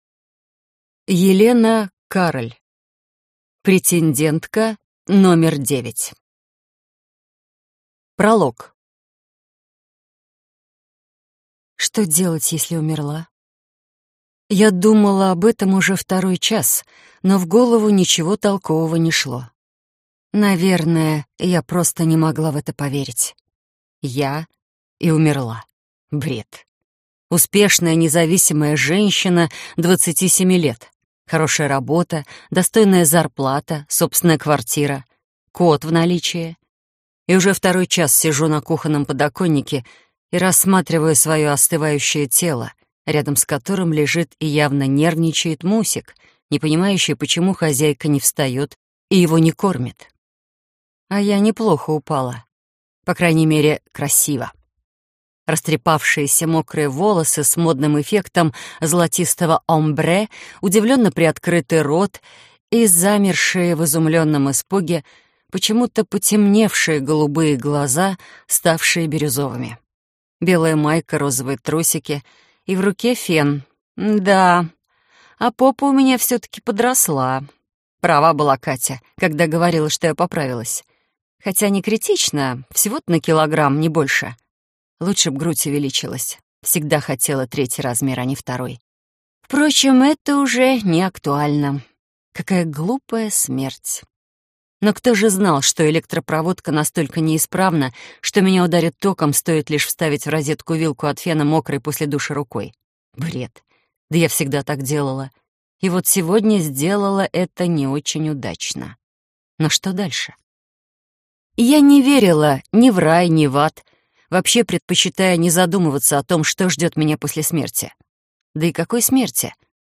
Аудиокнига Претендентка номер девять | Библиотека аудиокниг